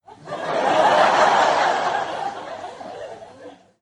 lachen.mp3